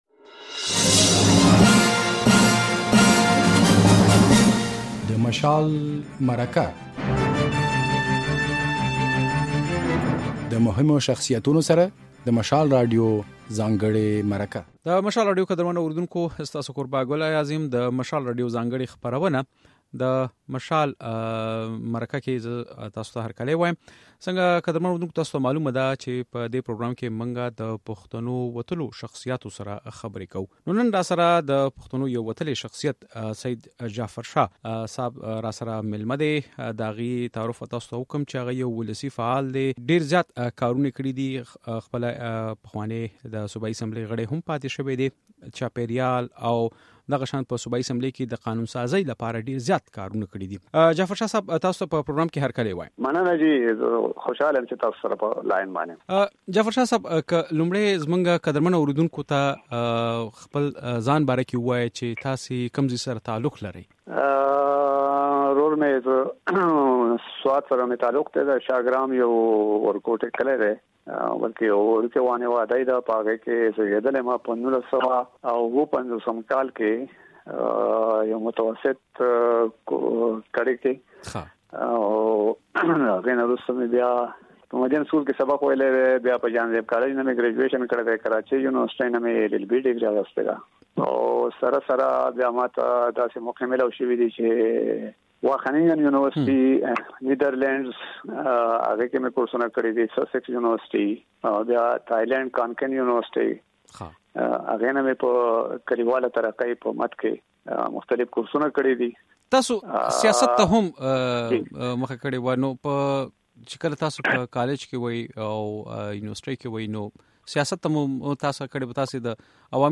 د مشال مرکه کې سياستوال، مدني فعال سيد جعفر شاه ميلمه دی. جعفر شاه د صوبايي اسمبلۍ دوه واري غړی پاتې شوي. نوموړي ځينې کتابونه هم ليکلي او اشوکه فيلوشپ جايزې هم ګټلې ده.